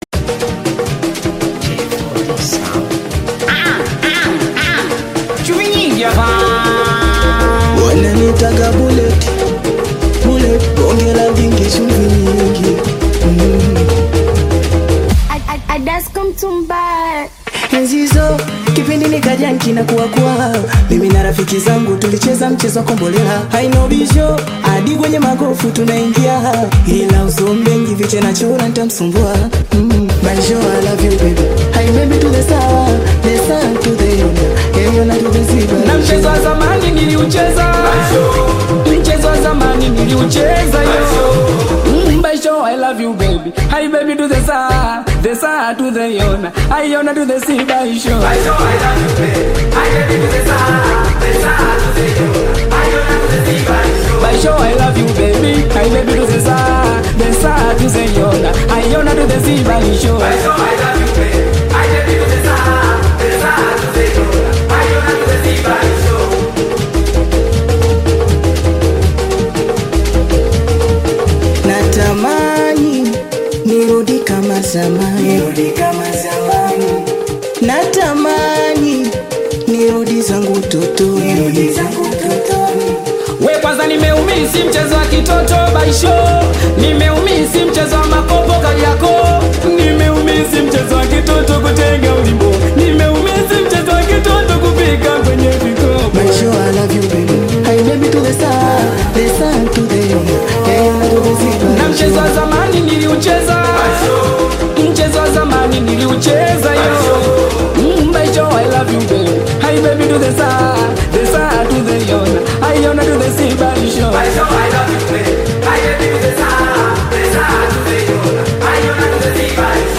Singeli music track
Tanzanian Bongo Flava singeli
Singeli song